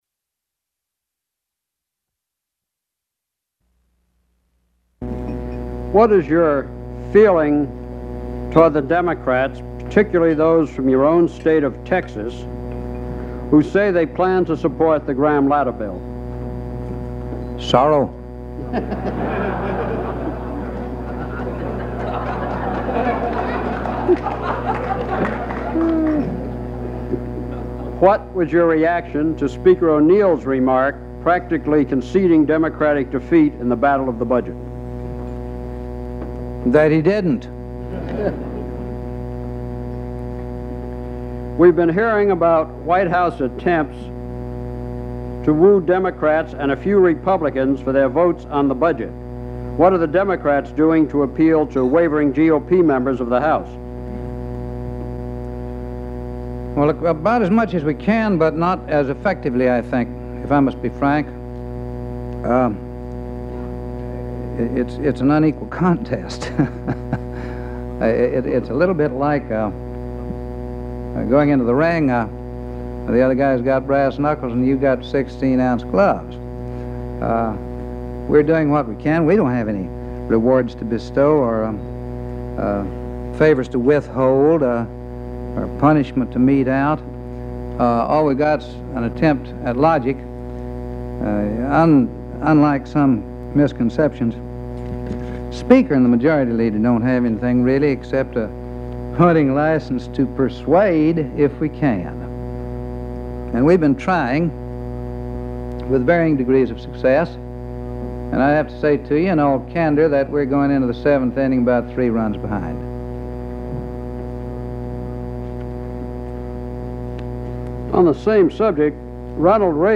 U.S. Congressman answers questions at a National Press Club luncheon
Subjects Reagan, Ronald Bryan, William Jennings, 1860-1925 Politics and government United States Material Type Sound recordings Language English Extent 00:12:02 Venue Note Broadcast 1981 May 6.